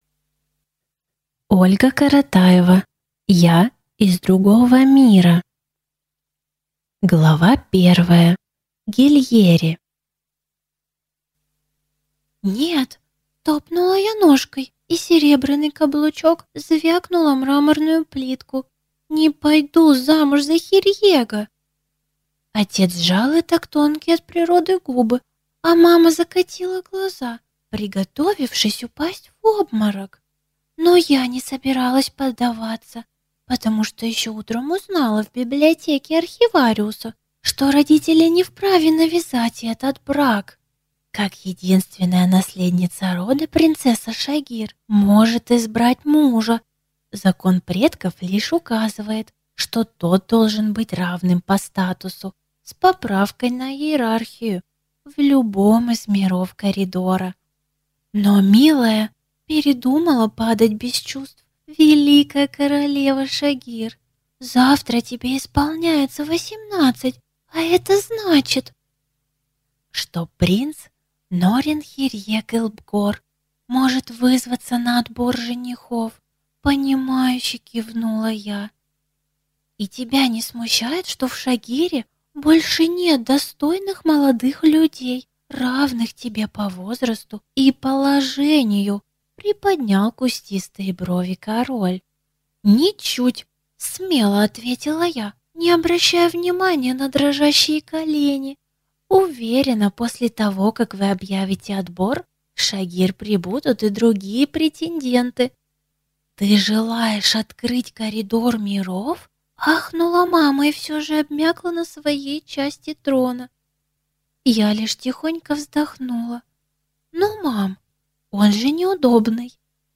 Аудиокнига «Я» из другого мира | Библиотека аудиокниг